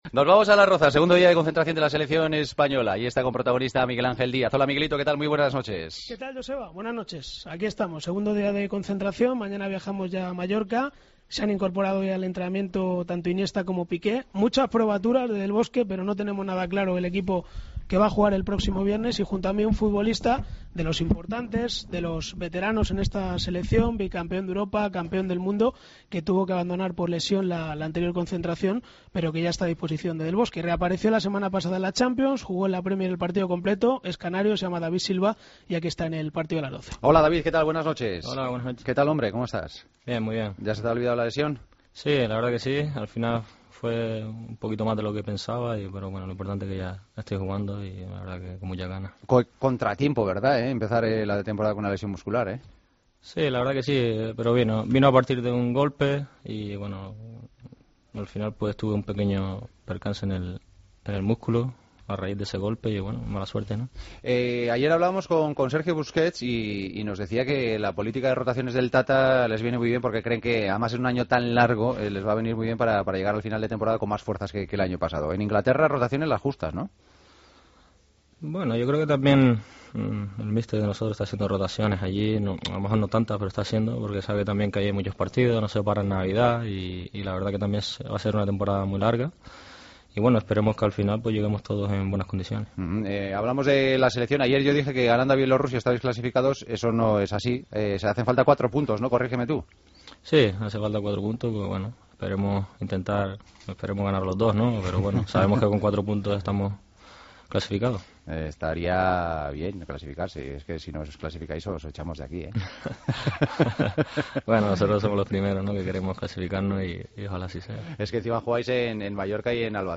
Entrevista a David Silva, en El Partido de las 12